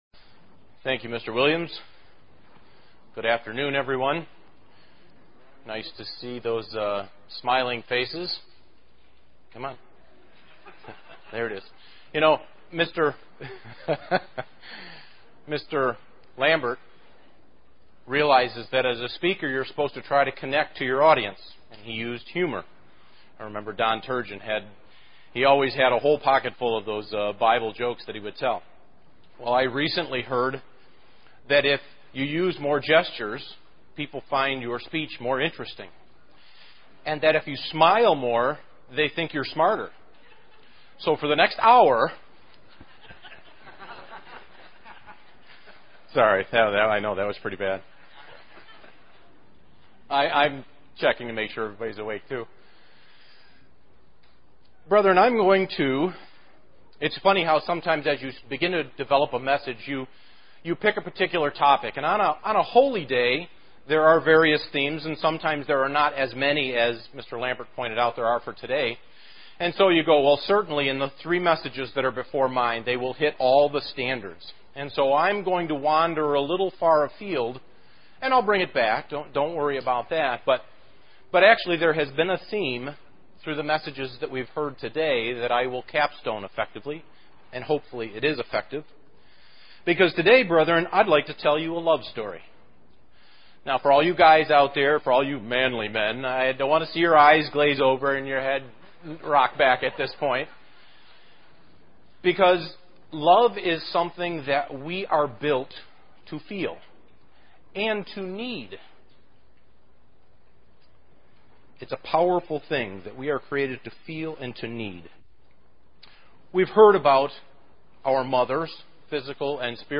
God guides us with His love. This message was given on the Feast of Pentecost.
UCG Sermon Studying the bible?